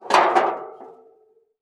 Metal_19.wav